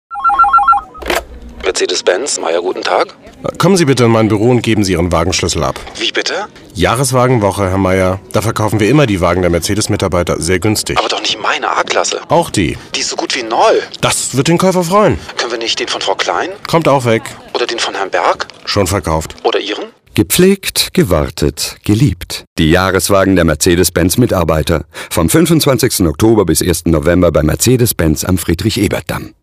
Funkspot zur Jahreswagen-Aktion